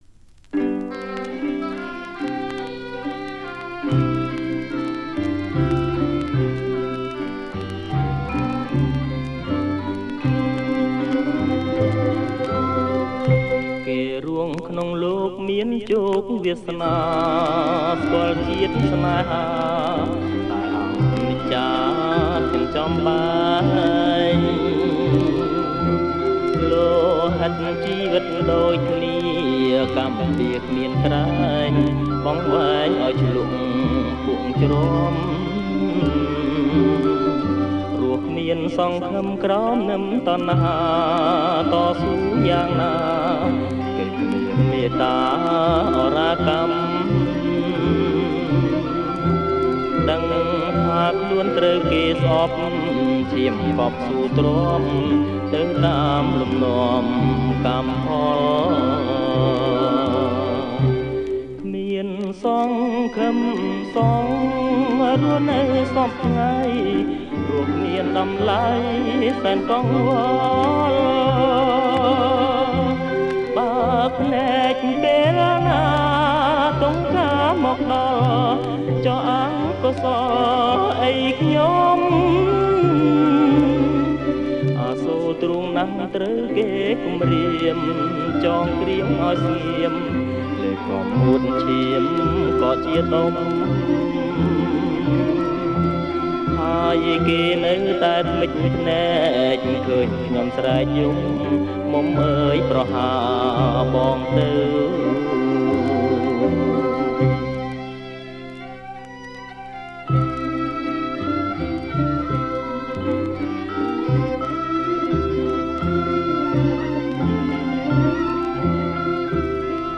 • ប្រគំជាចង្វាក់ Slow Folk